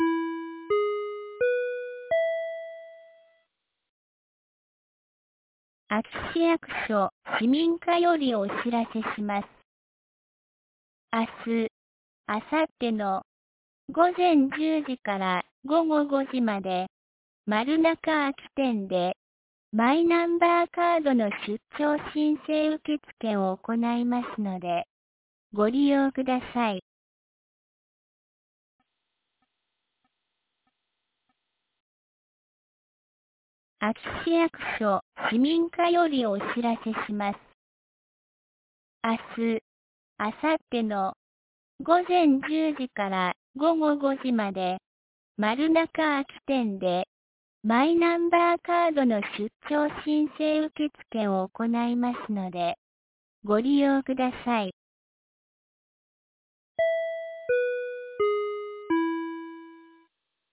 2022年09月09日 17時16分に、安芸市より全地区へ放送がありました。